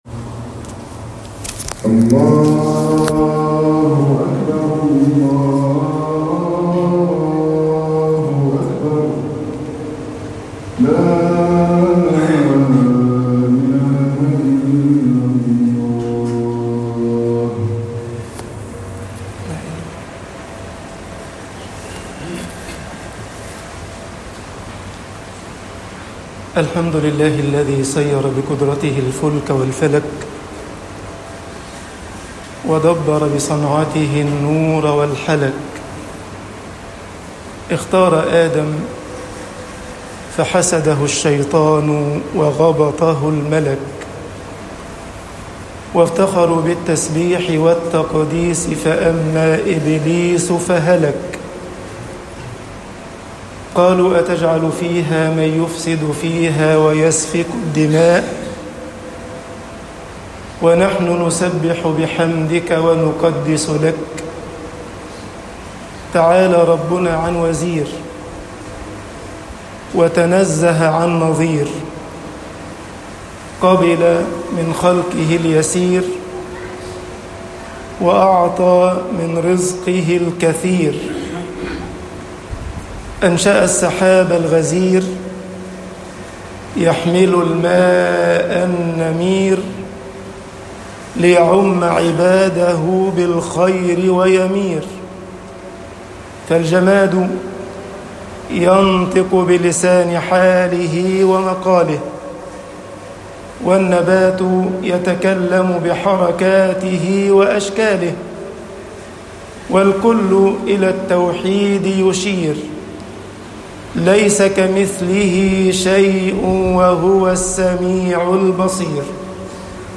خطب الجمعة - مصر النفسُ البشريةُ وحُبُ الشهوات طباعة البريد الإلكتروني التفاصيل كتب بواسطة